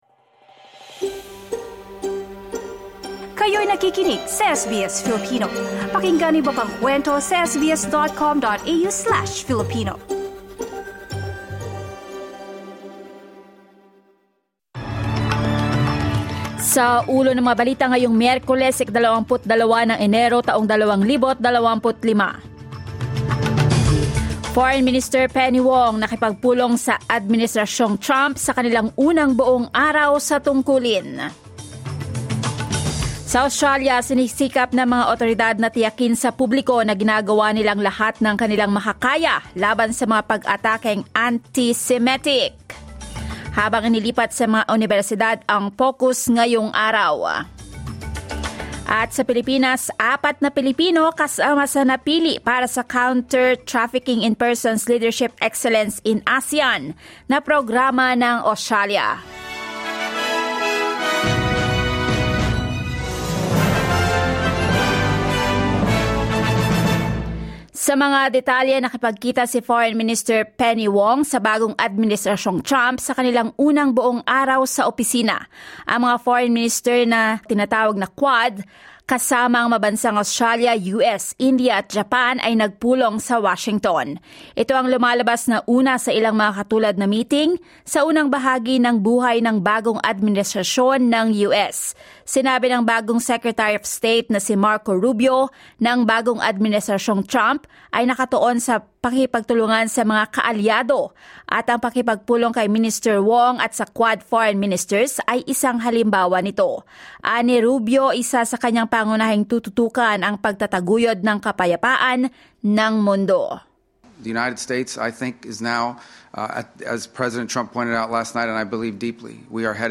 SBS News in Filipino, Wednesday 22 January 2025